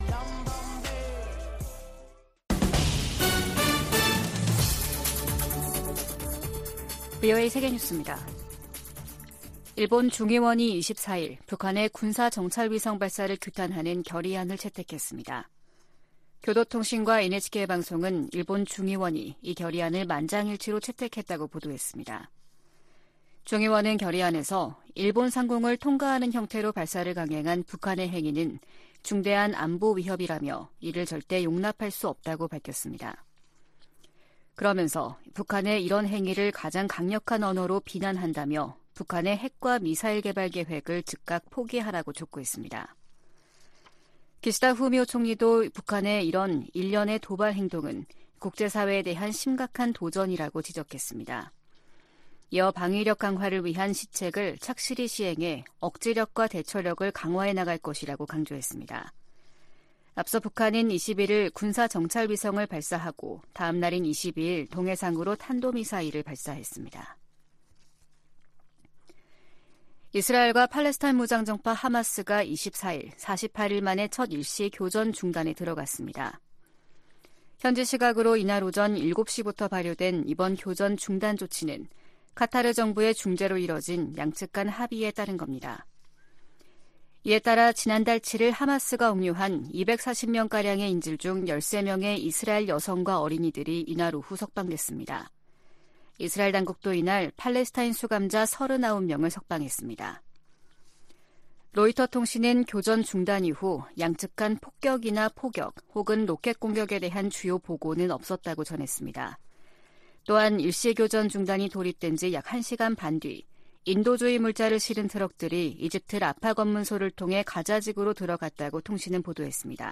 VOA 한국어 아침 뉴스 프로그램 '워싱턴 뉴스 광장' 2023년 11월 25일 방송입니다. 미국과 유럽연합(EU)이 국제원자력기구 정기이사회에서 북한의 무기개발을 규탄했습니다. 북한이 쏴 올린 정찰위성 만리경 1호가 빠른 속도로 이동하면서 하루에 지구를 15바퀴 돌고 있는 것으로 확인됐습니다. 한국-영국 정부가 북한 해킹 공격의 위험성을 알리는 합동주의보를 발표했습니다.